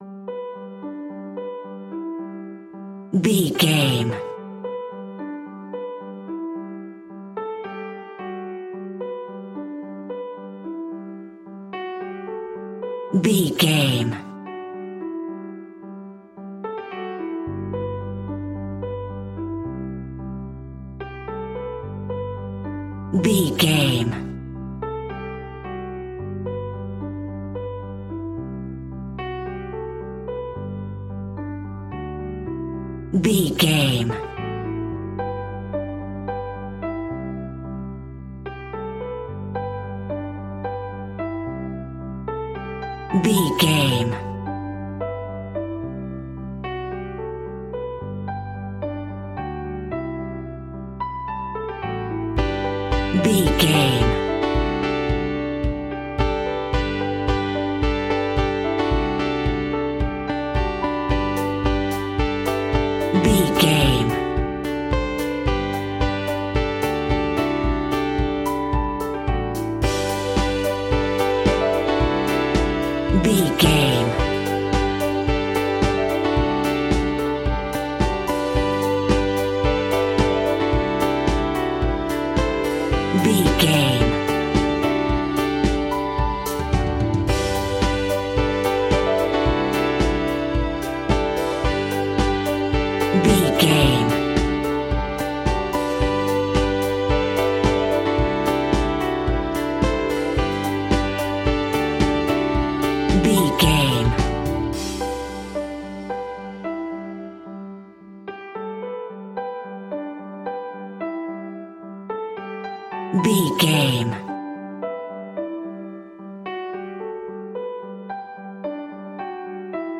Motivation Business Pop Rock Music Full.
Ionian/Major
pop rock
indie pop
energetic
uplifting
upbeat
groovy
guitars
bass
drums
piano
organ